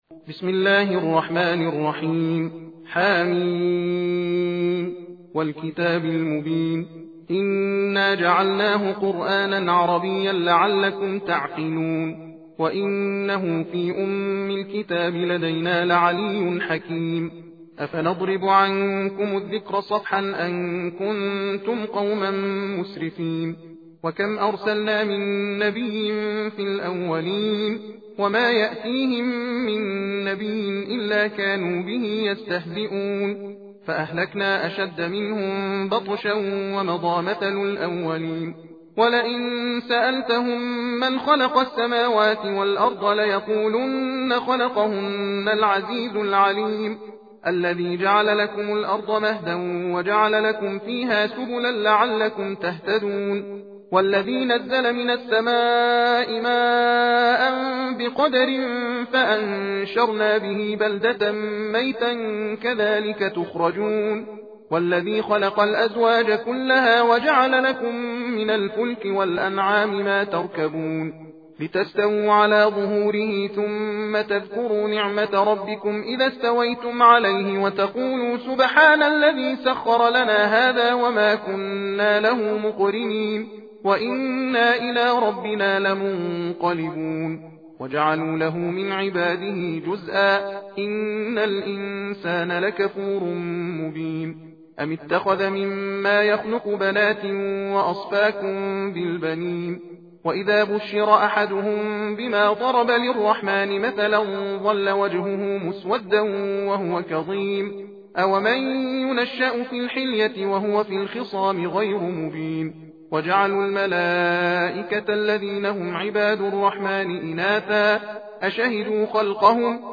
تحدیر (تندخوانی) سوره زخرف
تحدیر روشی از تلاوت قرآن است که قاری در آن علی رغم رعایت کردن قواعد تجوید، از سرعت در خواندن نیز بهره می برد، از این رو در زمان یکسان نسبت به ترتیل و تحقیق تعداد آیات بیشتری تلاوت می شود.به دلیل سرعت بالا در تلاوت از این روش برای مجالس ختم قرآن کریم نیز می توان بهره برد.